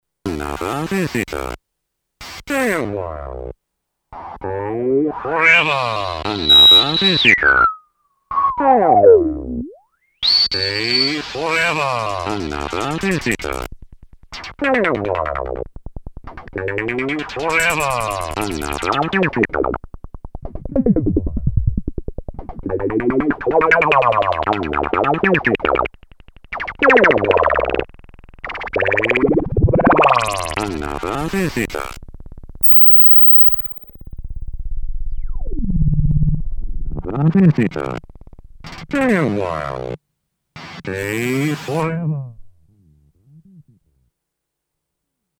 editSPEECH PROCESSED BY OTHER MACHINE I recorded some fun variations about the "another visitor" speech processed by different samplers, effects, vocoders or whatever!
Filter + LFO Korg MS20